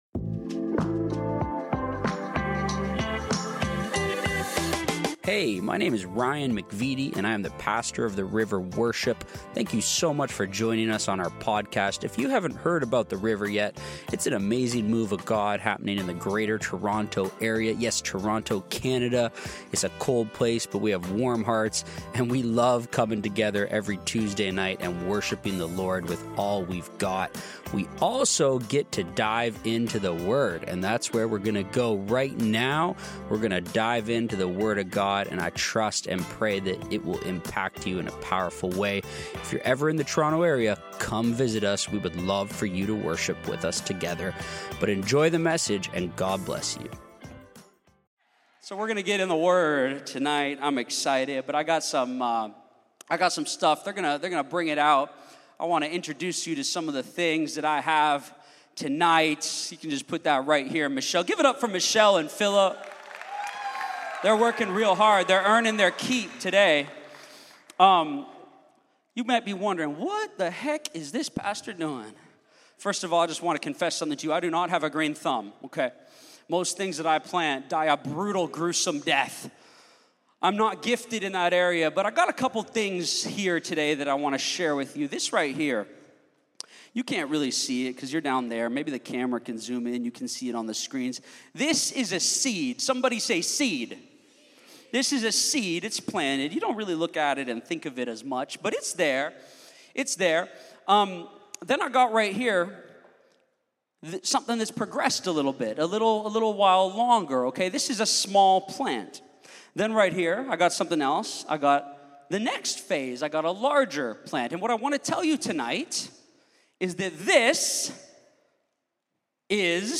The River Worship - Sermons – Podcast